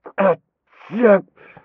m_pain_8.ogg